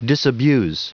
Prononciation du mot disabuse en anglais (fichier audio)
Prononciation du mot : disabuse